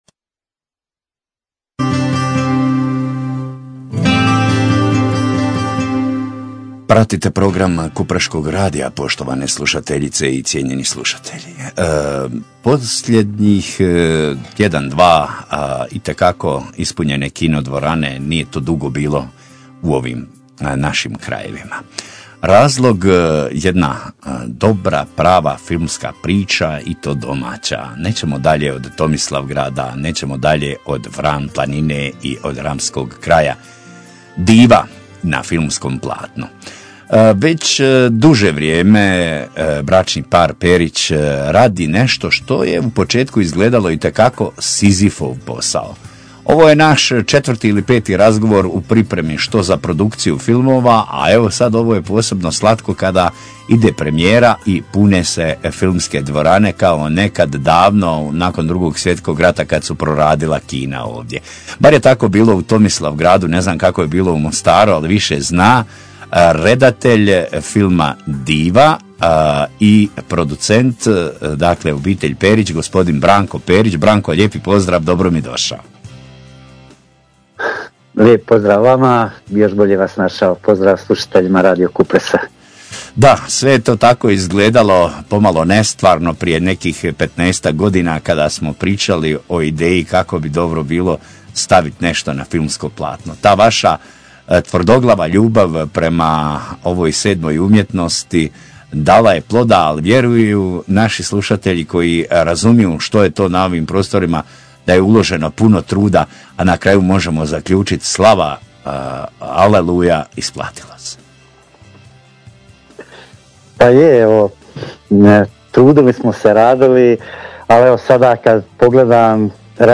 Razgovor